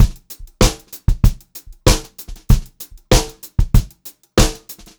96POPBEAT4-R.wav